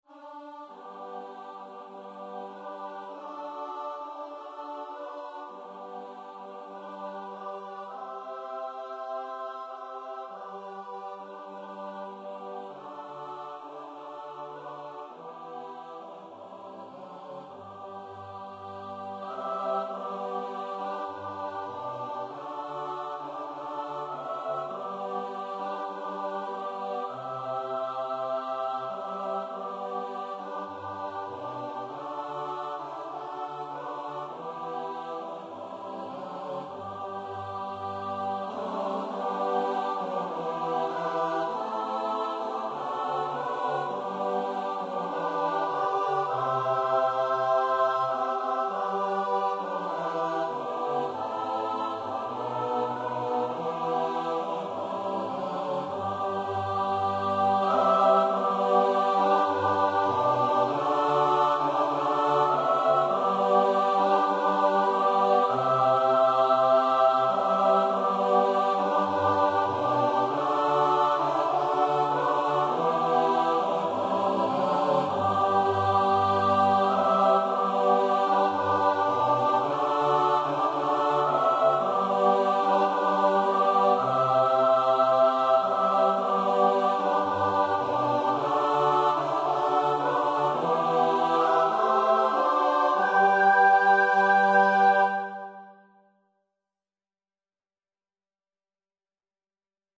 for mixed voice choir